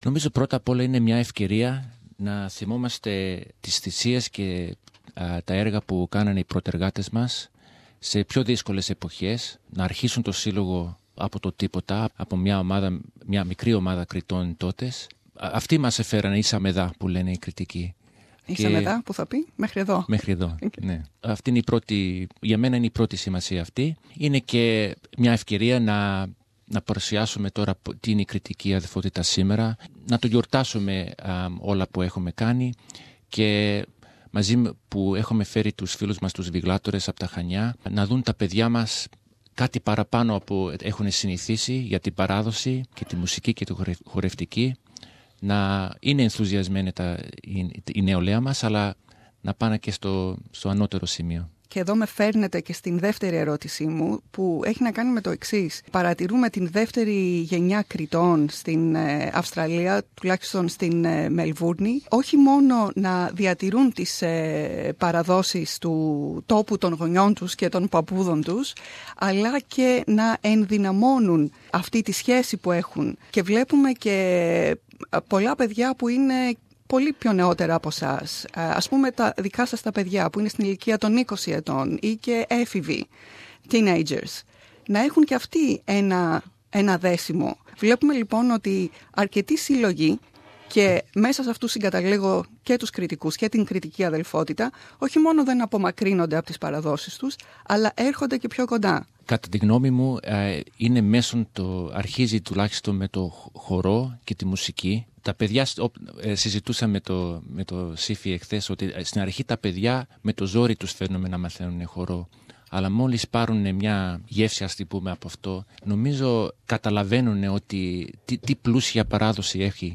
Πεντοζάλη με ιστορική σημασία.